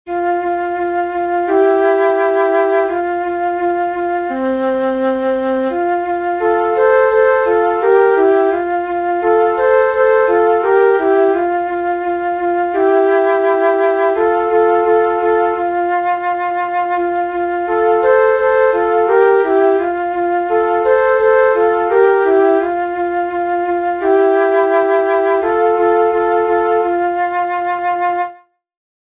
lidová píseň